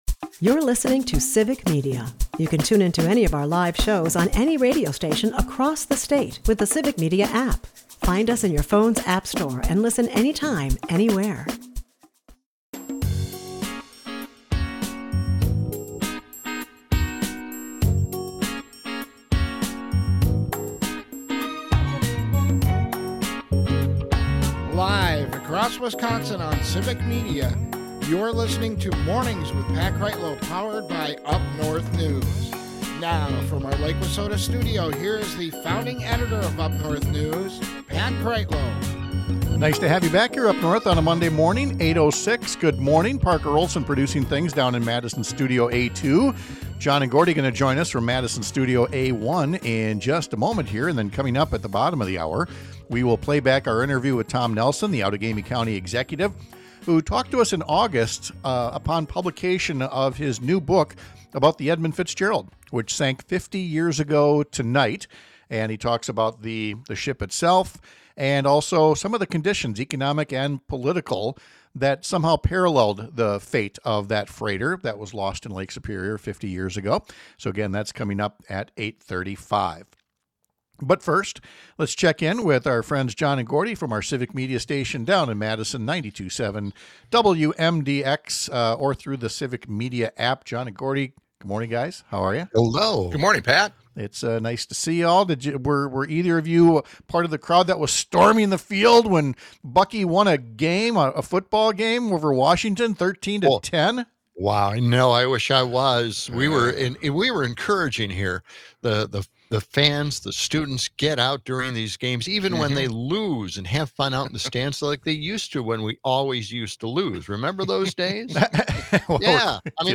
We’ll revisit our interview with Tom Nelson, the Outagamie County Executive, who’s written a book on the maritime disaster that also shows the ship as a fitting metaphor for the political and economic management of the late 20th century — putting shareholder profits ahead of workers’ prosperity.